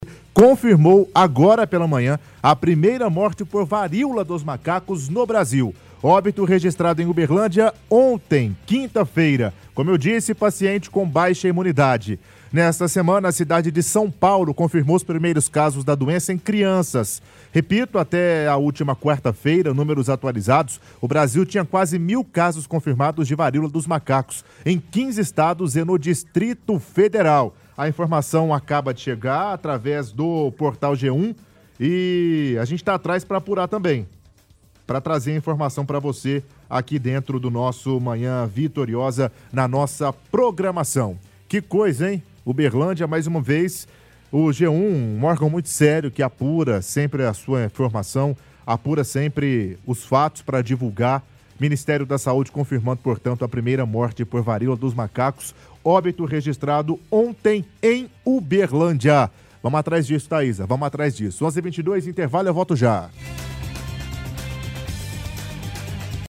– Leitura da matéria do portal G1